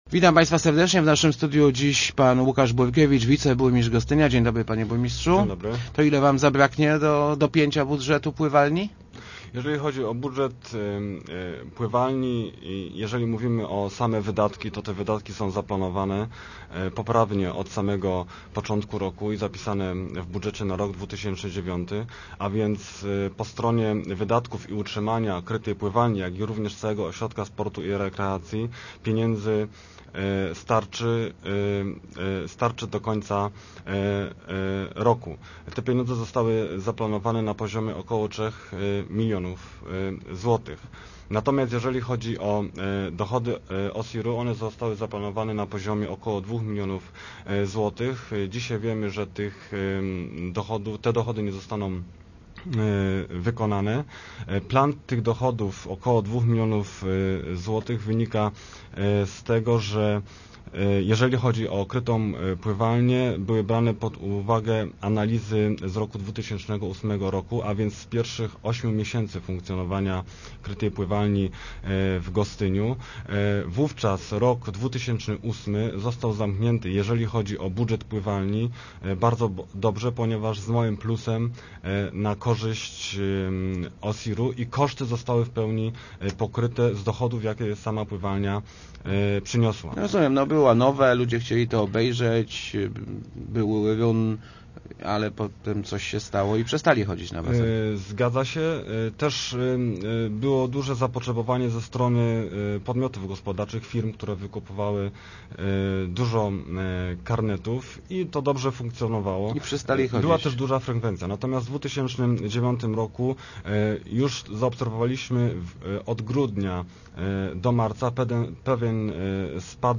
burkiewicz80.jpgCały czas uważamy, że wybudowanie krytej pływalni było dobrym pomysłem – zapewniał w Rozmowach Elki wiceburmistrz Gostynia Łukasz Burkiewicz. Pływalnia po kilkunastu miesiącach jest deficytowa – „dziura” między kosztami a wpływami urosła o około 400 tysięcy.